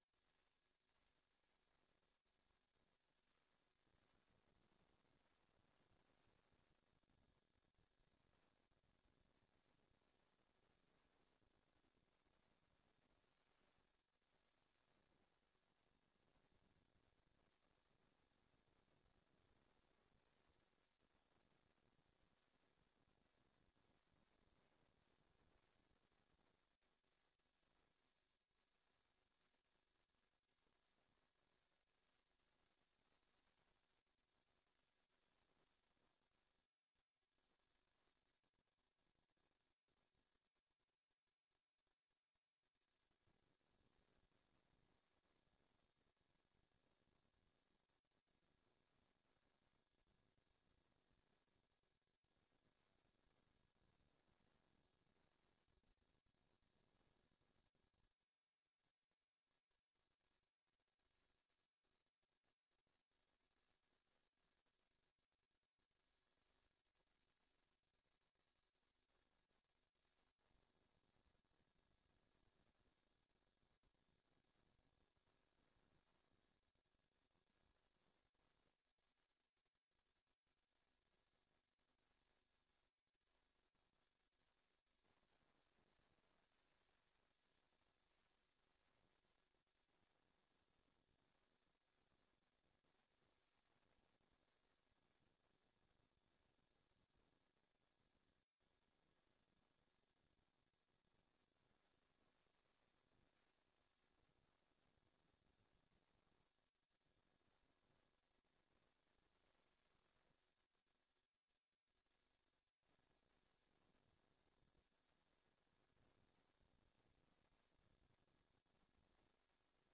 Download de volledige audio van deze vergadering
Ook worden deze vergaderingen live uitgezonden via onze website en blijven beschikbaar, in het openbaar archief van de website, om terug te kijken.